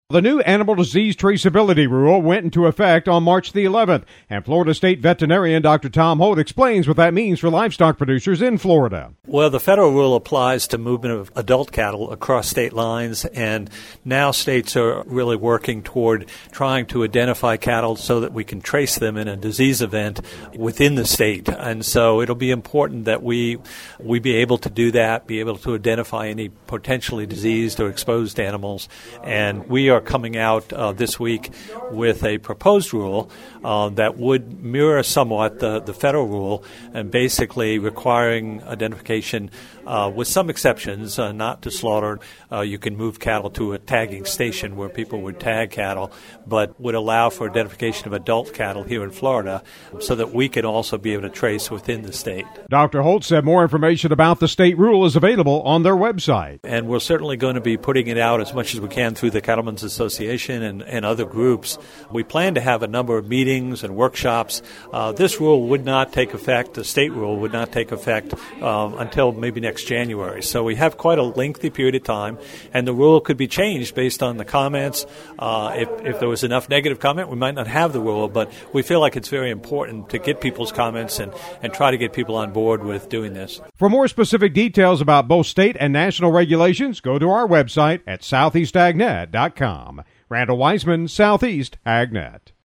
Dr. Tom HoltOn March 11th, the new Federal Animal Disease Traceability rule went into effect. And as individual states are working to put together their plans, Florida State Veterinarian Dr. Tom Holt explains what this means for livestock producers in his state.